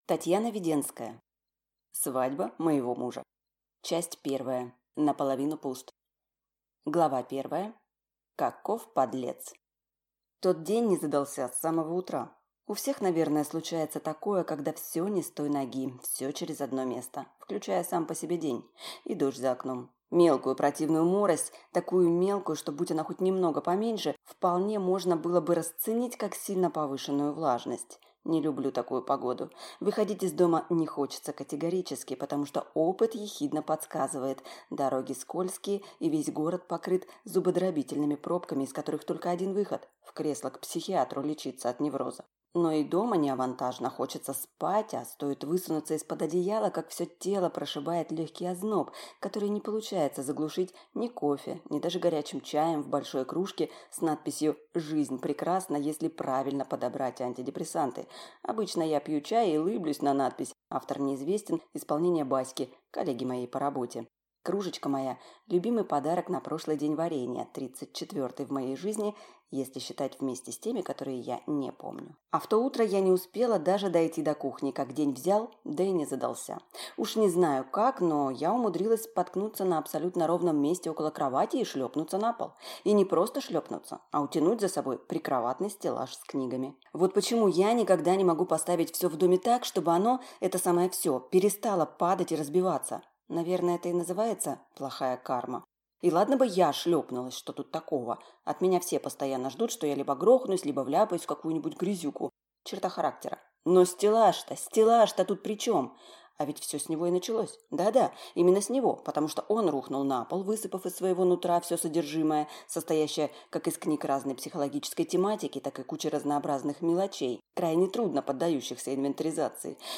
Аудиокнига Свадьба моего мужа | Библиотека аудиокниг